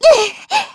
Xerah-Vox_Damage_kr_01.wav